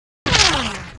bullet.wav